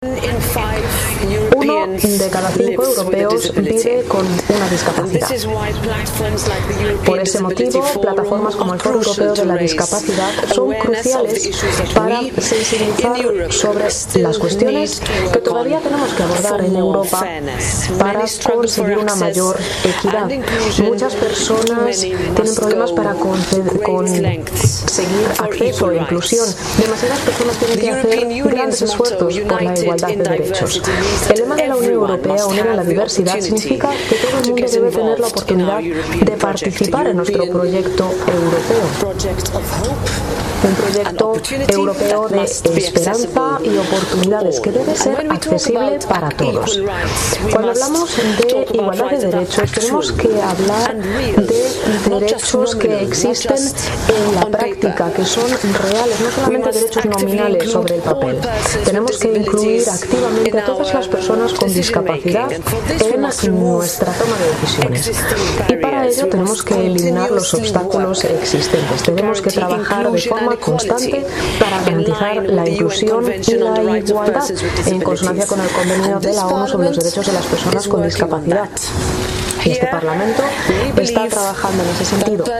dijo Roberta Metsola formato MP3 audio(1,58 MB)presidenta del PE en la inauguración.